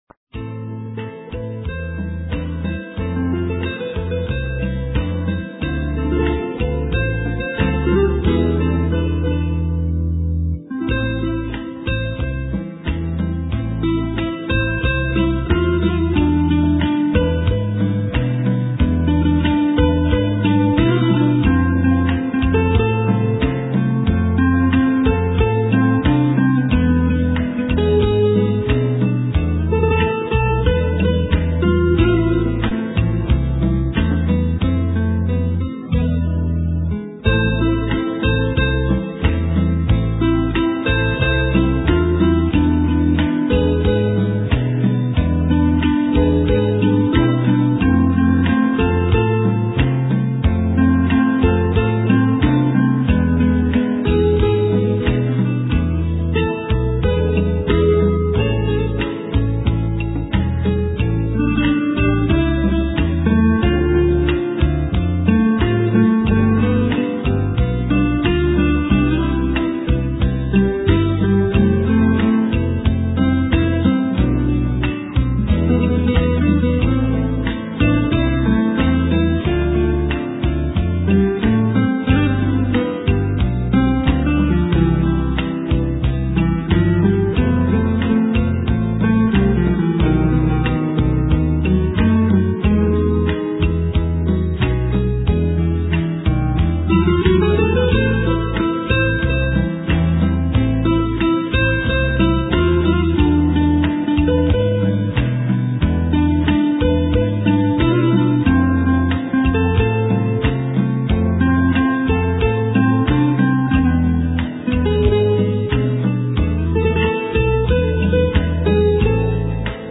* Ca sĩ: Không lời
* Thể loại: Ngoại Quốc